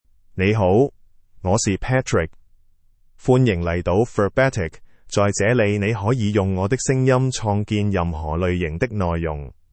MaleChinese (Cantonese, Hong Kong)
Patrick is a male AI voice for Chinese (Cantonese, Hong Kong).
Voice sample
Listen to Patrick's male Chinese voice.
Patrick delivers clear pronunciation with authentic Cantonese, Hong Kong Chinese intonation, making your content sound professionally produced.